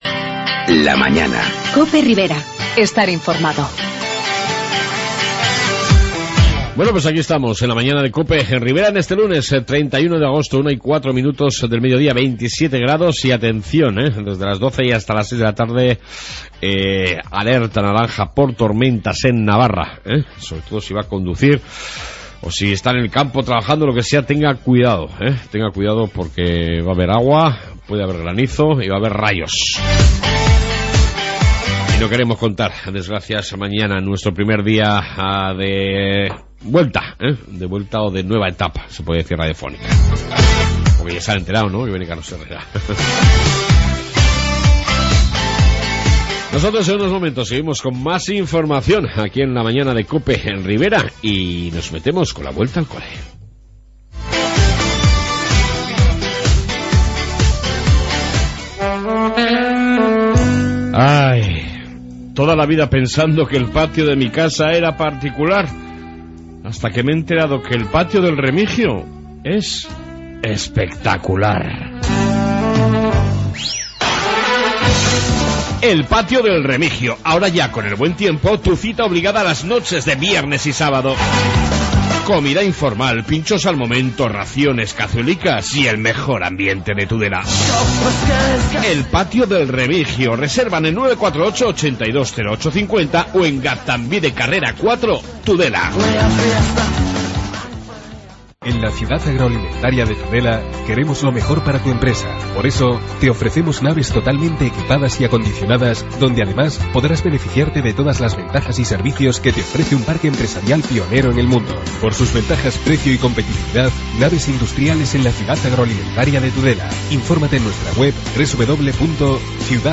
AUDIO: Seguimos con noticias locales y comarcales y entrevista sobre lo que se gastan los Padres navarros en "la vuelta al cole"